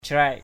/craɪʔ/ (đg.) khắc. crac angan di phun kayau cC aZN d} f~N ky~@ khắc tên vào thân cây.
crac.mp3